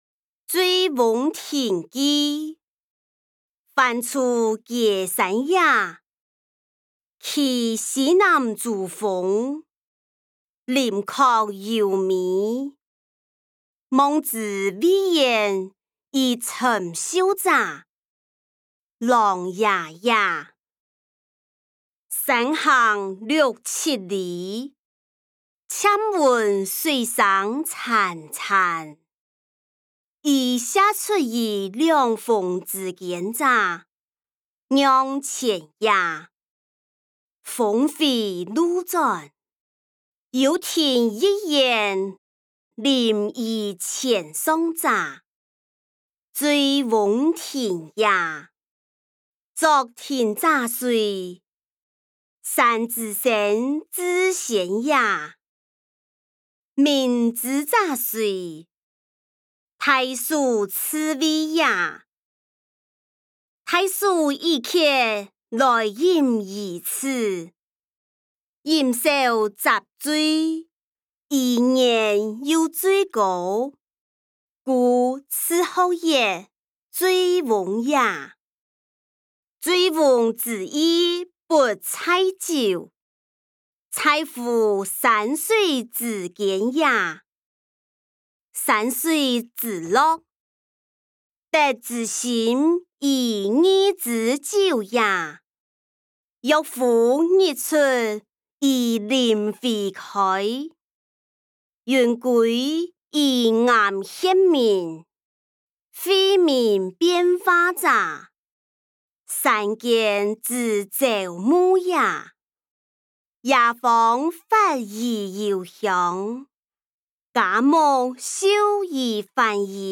歷代散文-醉翁亭記音檔(四縣腔)